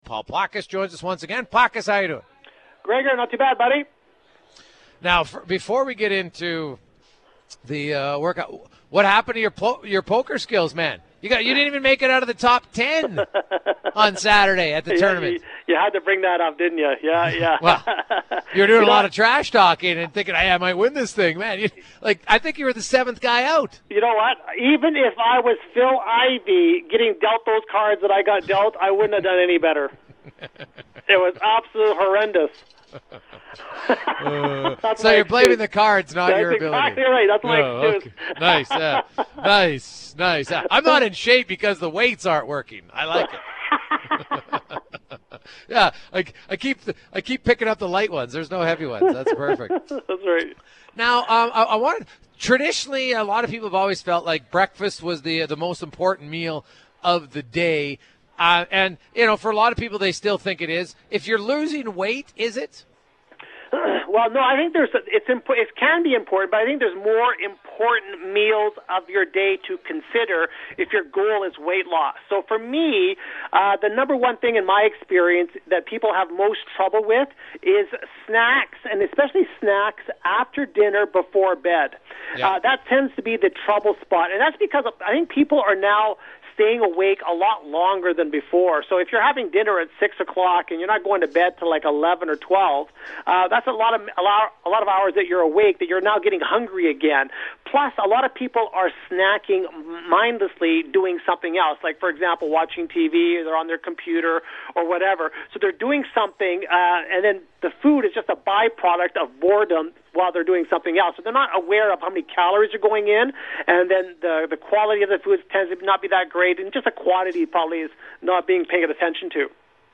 June 8th, 2016 – Live from the 14th Annual TSN 1260 Golf Tournament at The Ranch